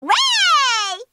weiii daitaku helios Meme Sound Effect